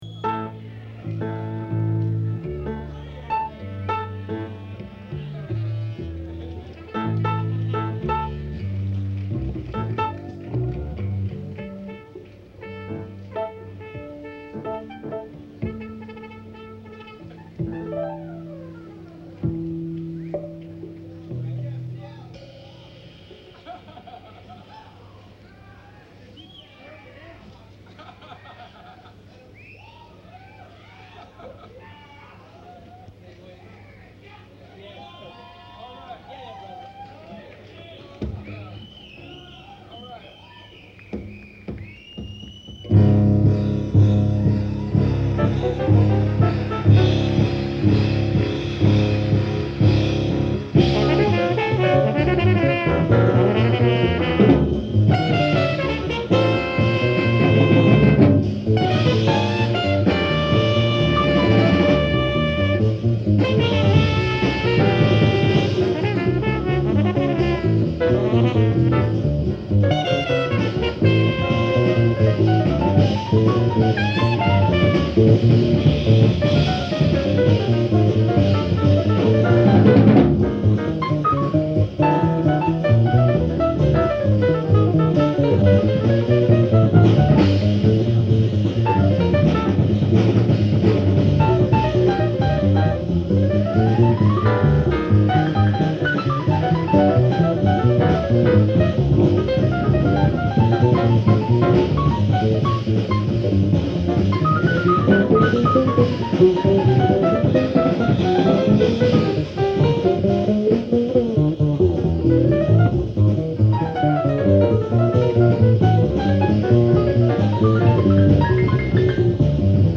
keyboards
guitar
trumpet
percussion